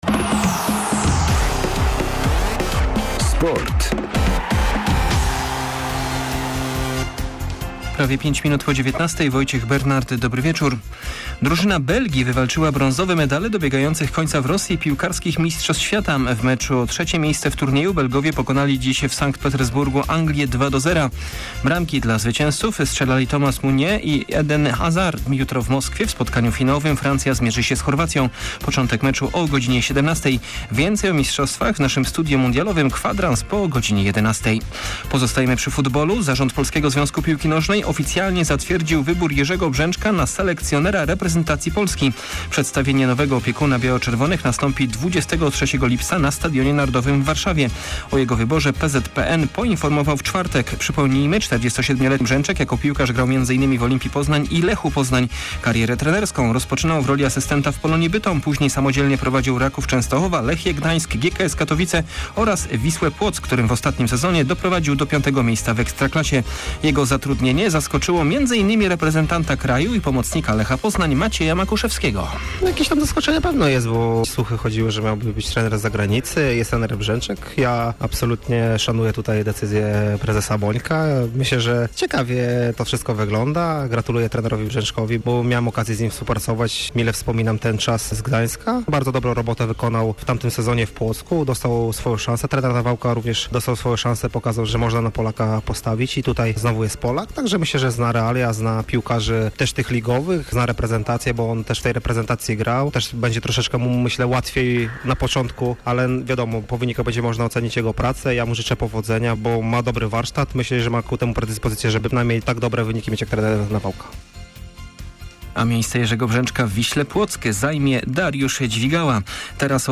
14.07 serwis sportowy godz. 19:05